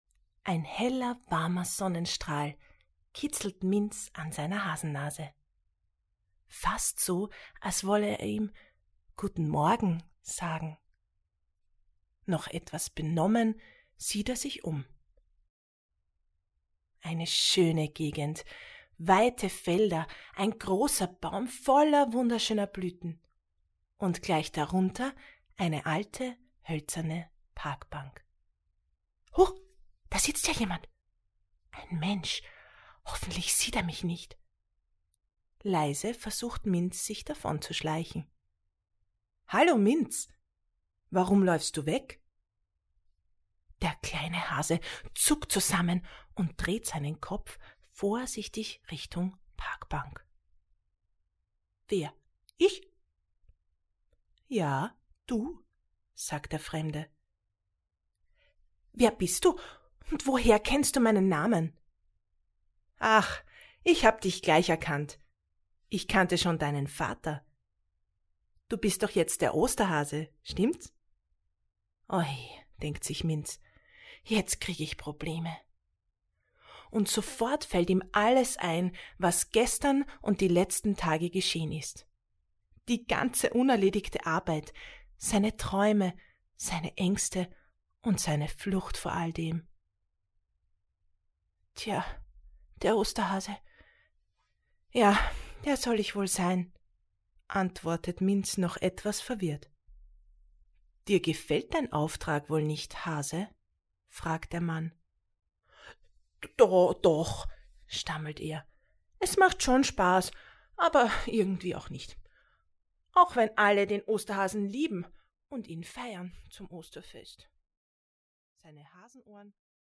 Autorenlesung (MP3)